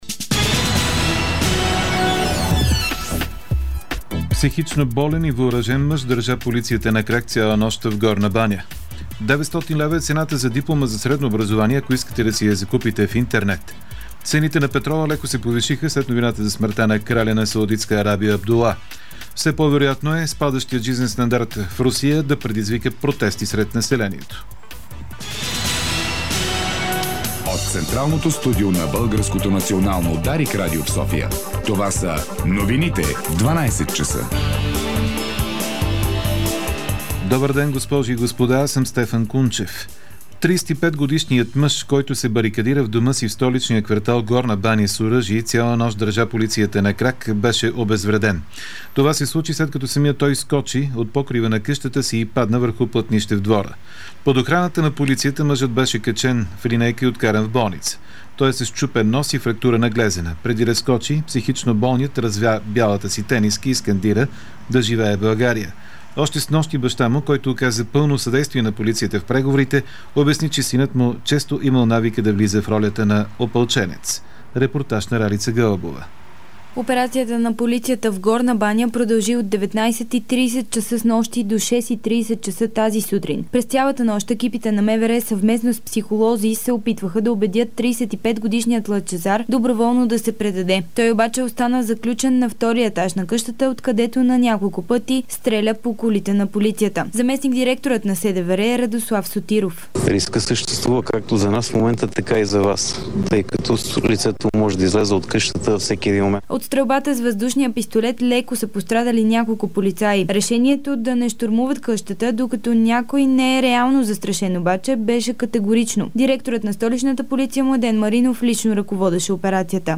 Обедна информационна емисия